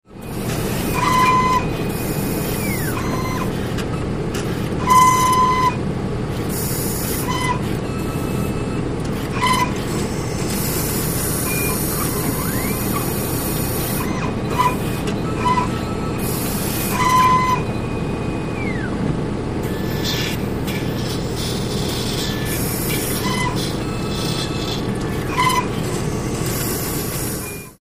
BloodAnalyzerMotor PE266701
Blood Analyzer 1; Blood Analysis Machine; Fan / Motor, Mixing, Air Releases, Printout, Beeps; Close Perspective. Hospital, Lab.